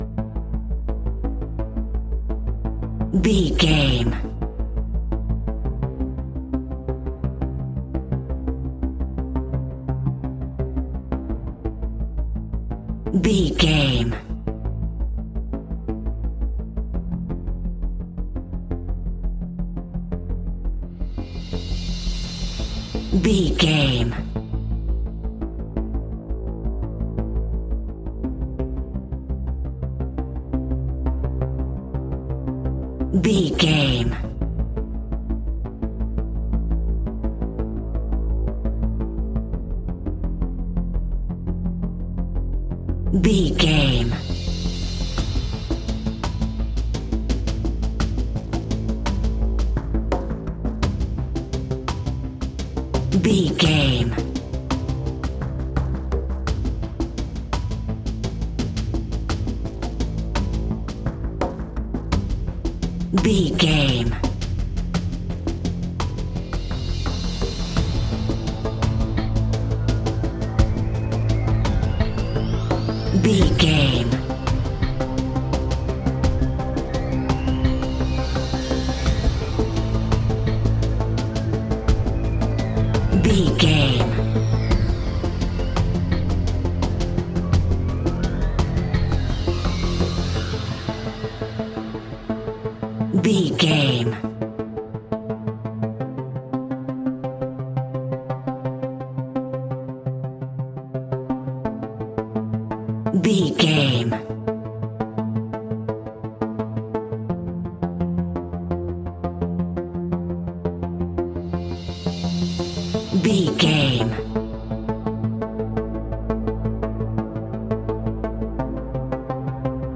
In-crescendo
Thriller
Aeolian/Minor
ominous
haunting
eerie
strings
synthesiser
drums
horror music